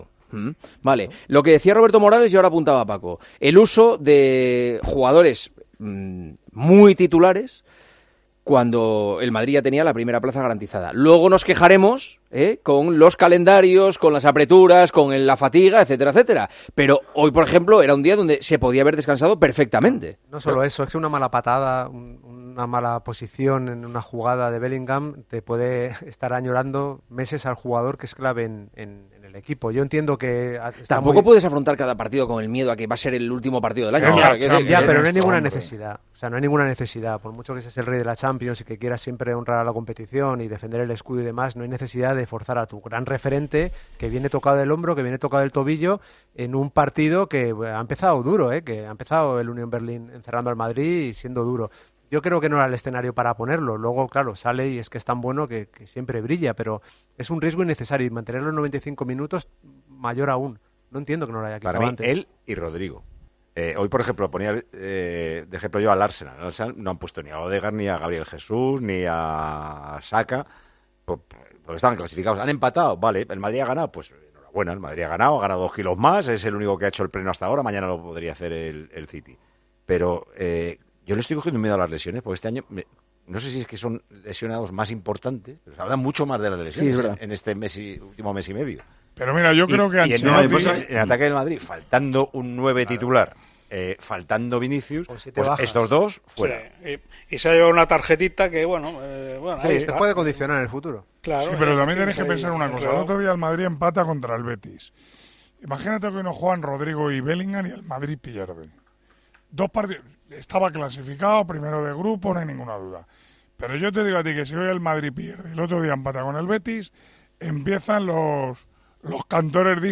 El director de Tiempo de Juego analizó en El Partidazo el riego que tomó el Real Madrid y Ancelotti alineando a los jugadores titulares cuando no se jugaba nada en el partido.